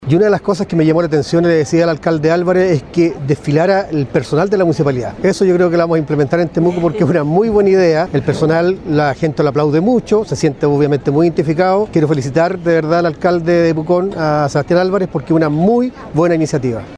Cientos de asistentes llegaron el viernes último al frontis de la Municipalidad de Pucón, en donde prácticamente todas las fuerzas vivas de la urbe lacustre se reunieron para festejar los 143 años de existencia de esta reconocida urbe turística en un desfile cívico, del que fueron parte más de 40 organizaciones.